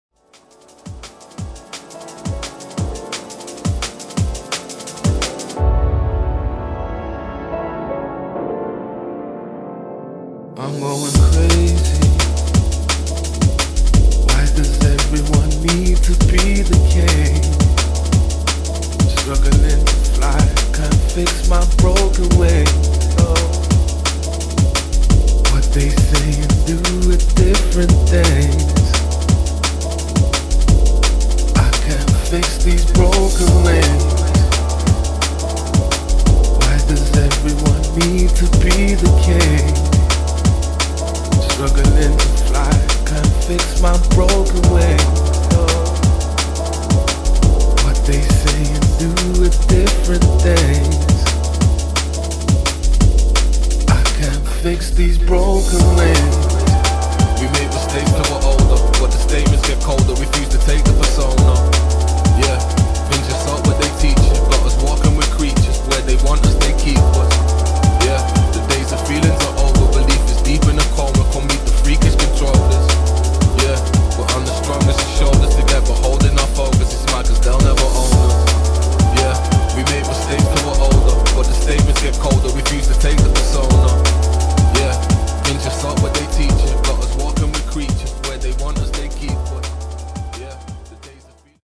DRUM'N'BASS | JUNGLE